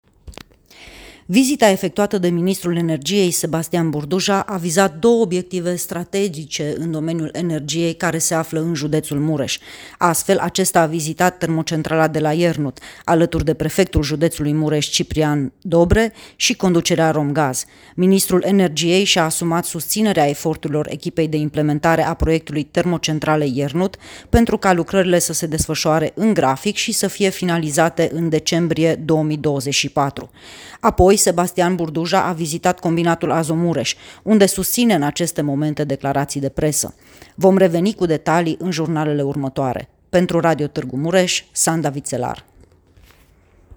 relatare